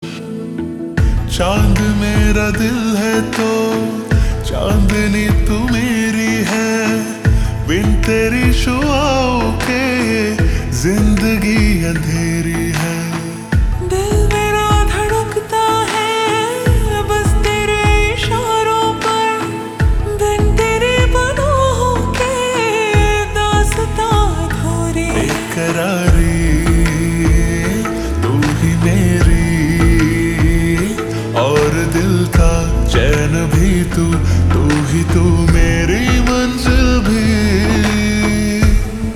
Slow Reverb Version
• Simple and Lofi sound
• Crisp and clear sound